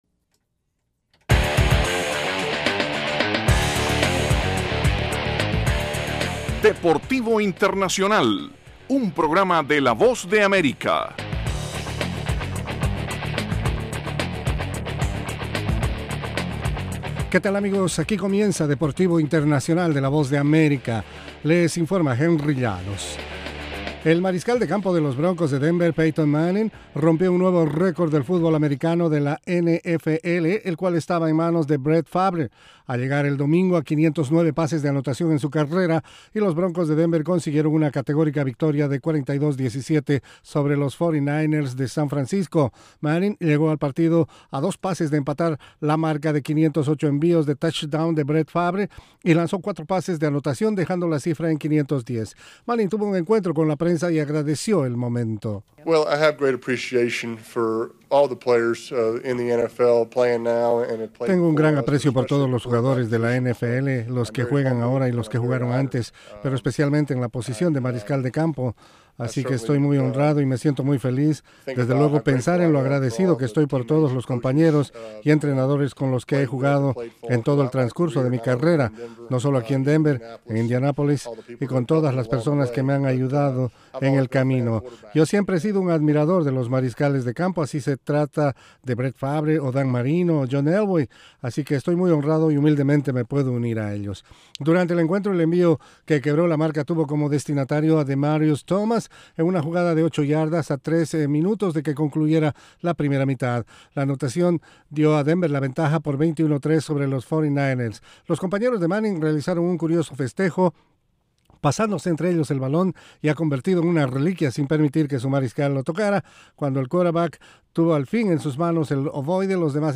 las noticias más relevantes del mundo deportivo desde los estudios de la Voz de América